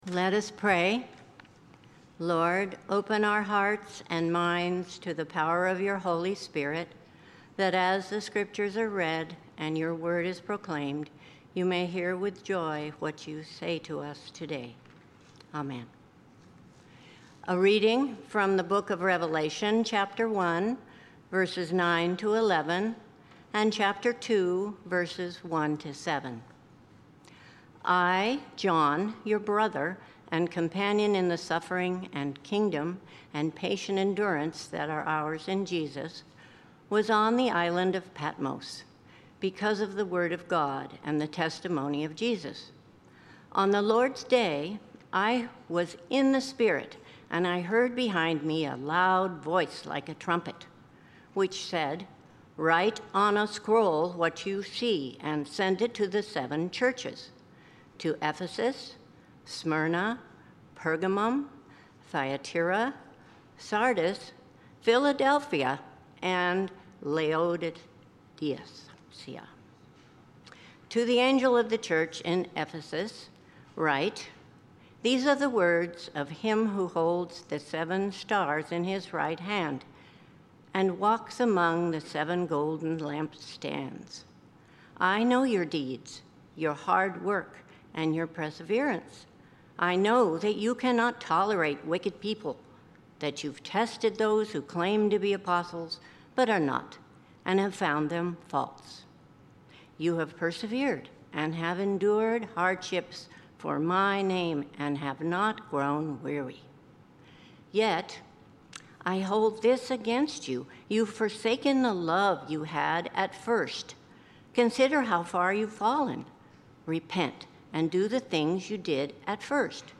Passage: Revelation 1:9–11, Revelation 2:1–7 Service Type: Sunday Morning